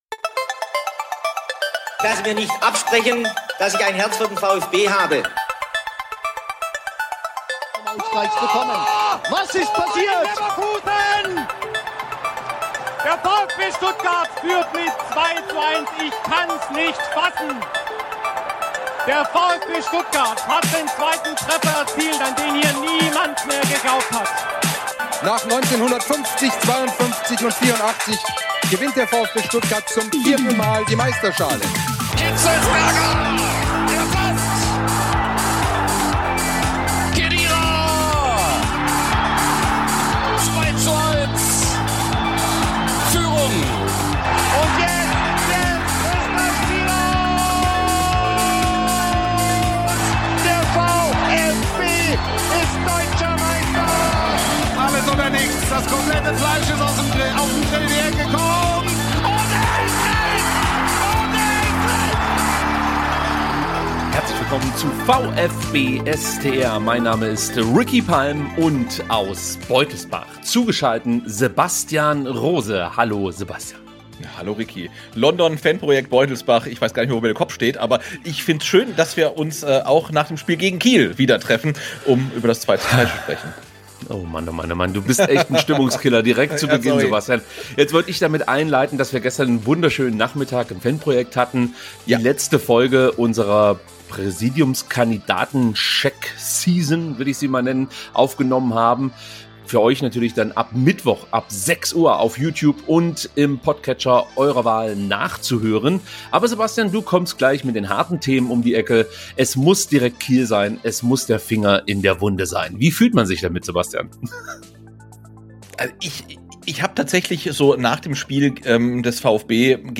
Der Podcast bietet eine Mischung aus Spielanalysen, Vereinsneuigkeiten, Interviews und Diskussionen rund um den Verein.